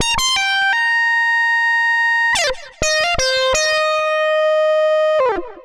AM_CopMono_85-C.wav